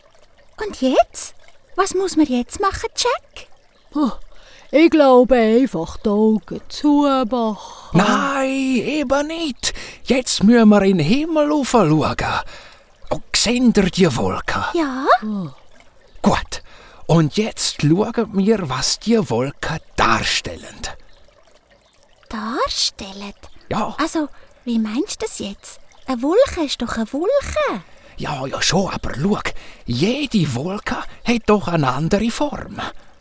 Dialekt Hörspiel